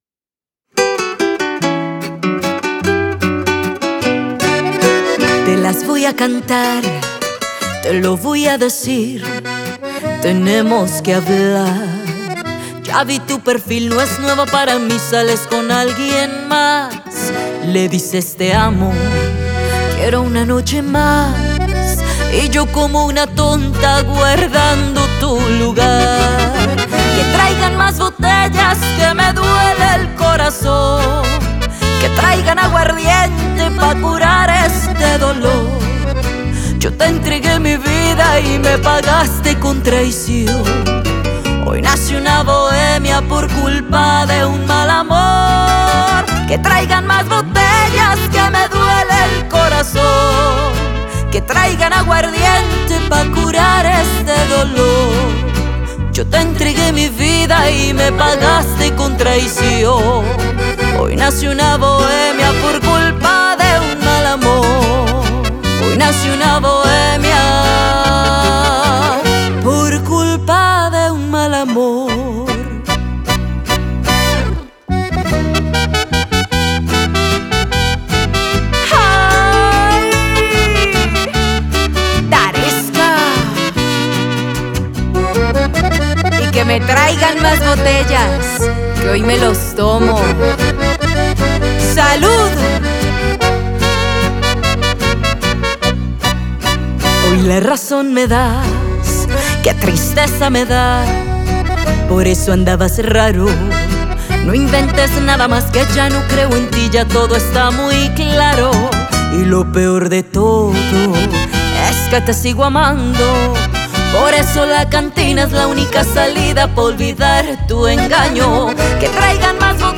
Este nuevo éxito de desamor